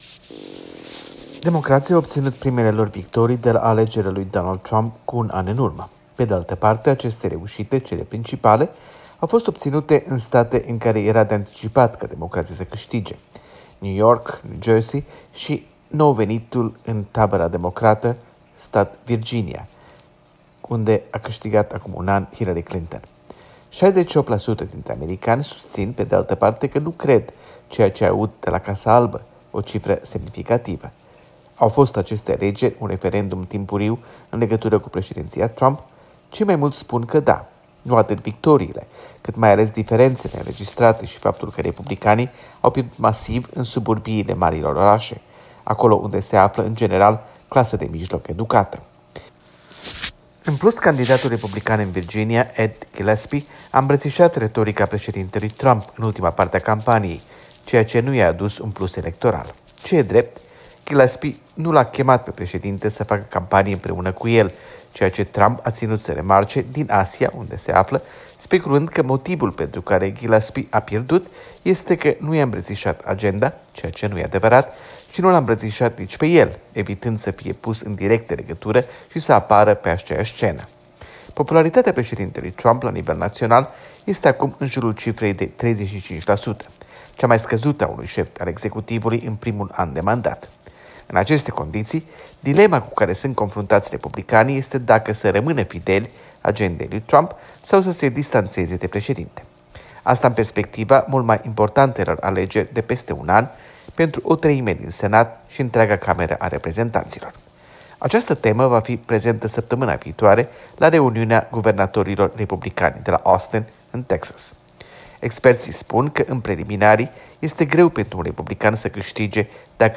Corespondența zilei de la Washington.